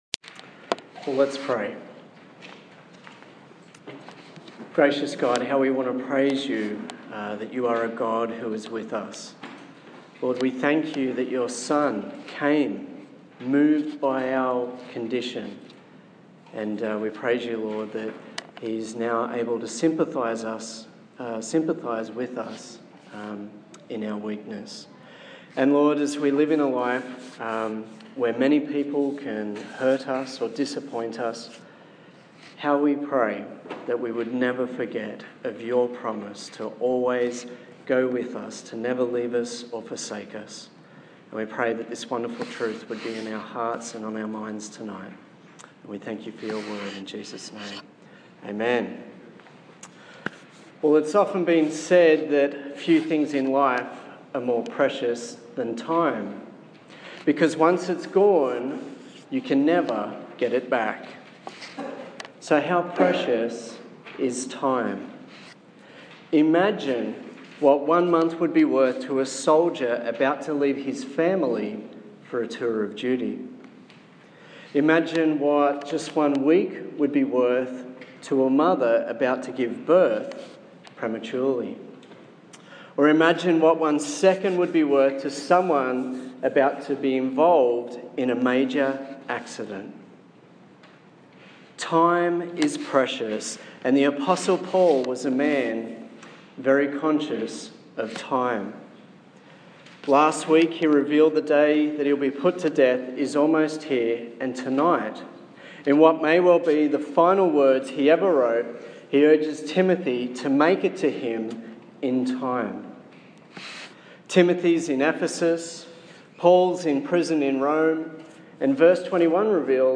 The nineth sermon in the series on 2 Timothy